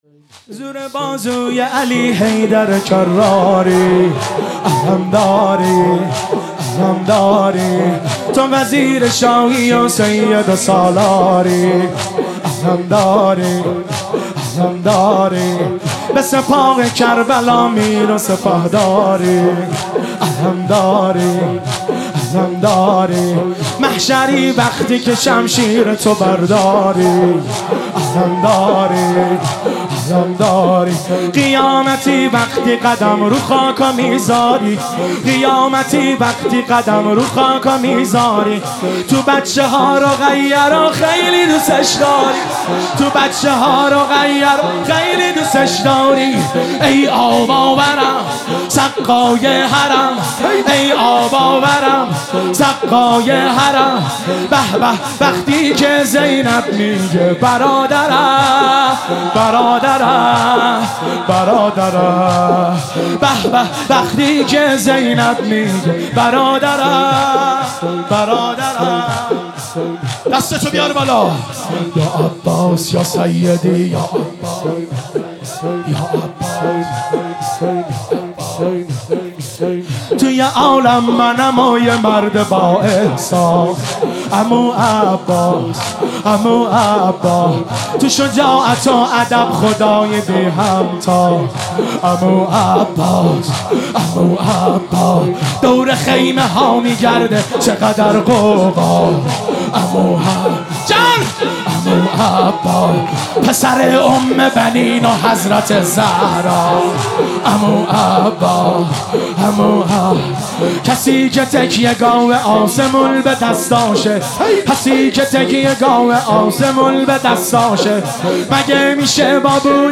مناسبت : شهادت حضرت فاطمه زهرا سلام‌الله‌علیها